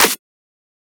edm-clap-47.wav